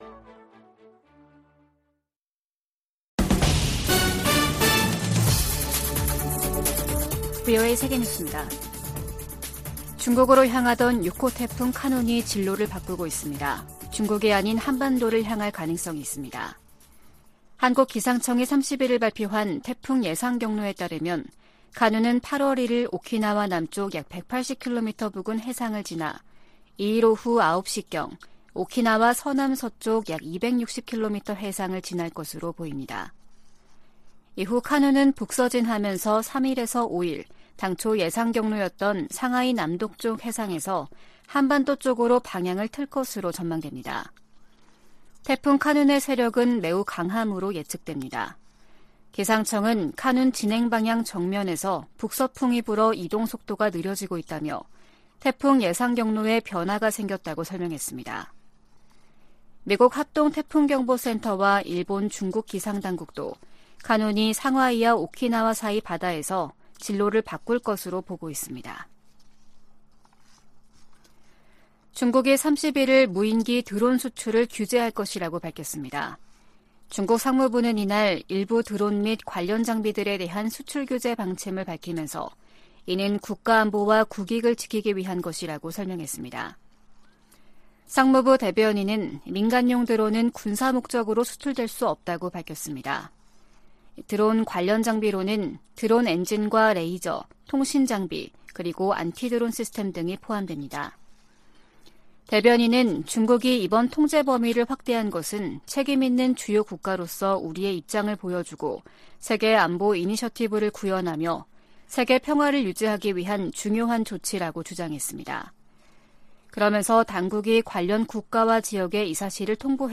VOA 한국어 아침 뉴스 프로그램 '워싱턴 뉴스 광장' 2023년 8월 1일 방송입니다. 백악관이 미한일 3국 정상회담 개최를 공식 발표하며 북한 위협 대응 등 협력 확대 방안을 논의할 것이라고 밝혔습니다. 미 국무부는 줄리 터너 북한인권특사 지명자에 대한 상원 인준을 환영했습니다. 미 상원이 2024회계연도 국방수권법안을 통과시켰습니다.